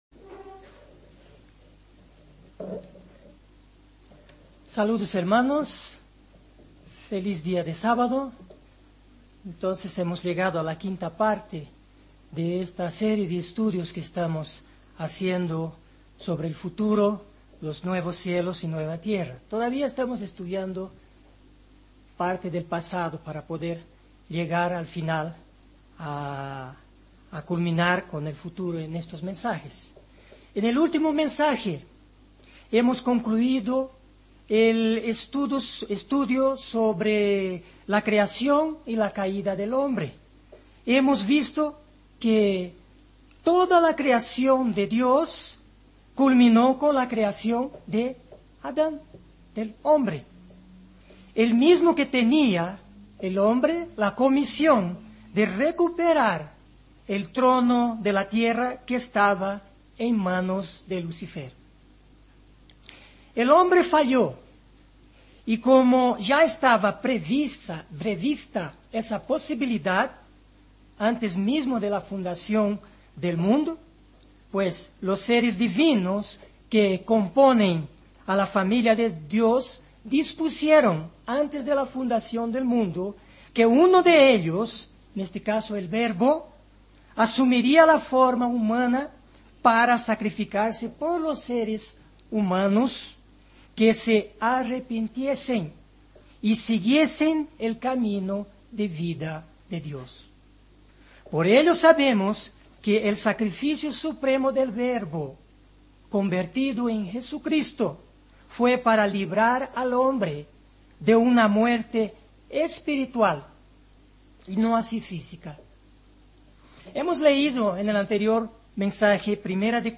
Given in Ciudad de México